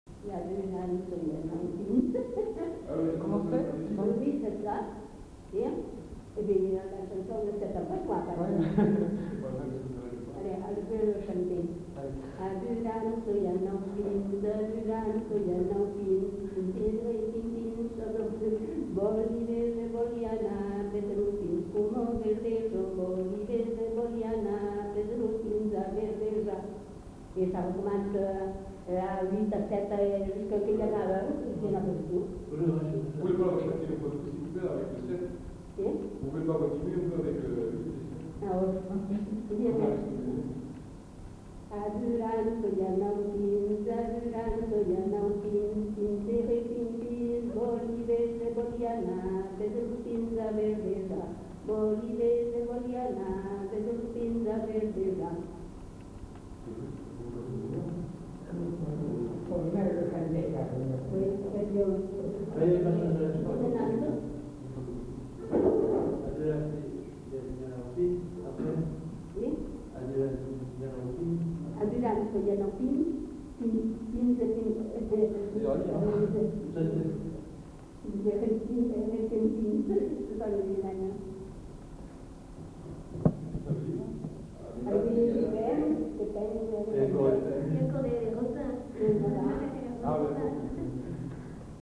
Lieu : Moncaut
Genre : chant
Effectif : 1
Type de voix : voix de femme
Production du son : chanté
Danse : rondeau
Classification : chansons de neuf